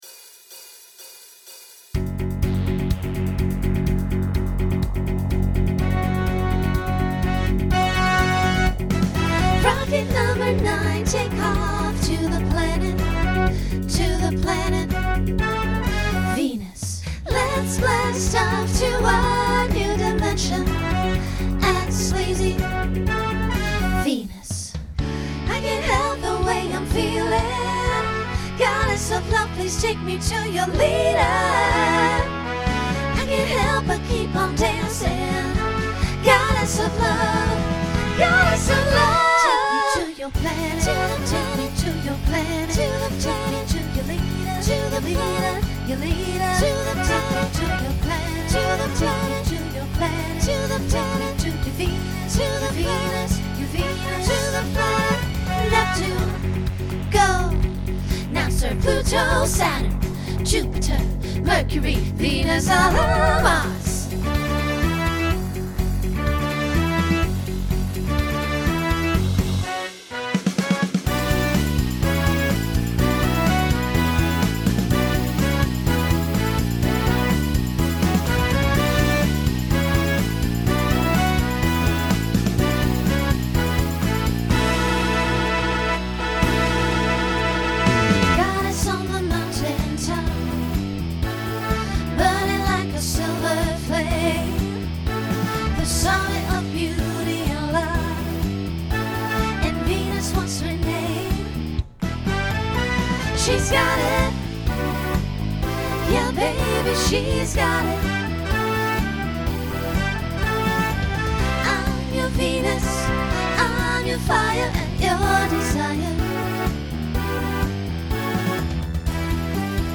Genre Pop/Dance , Rock Instrumental combo
Voicing SSA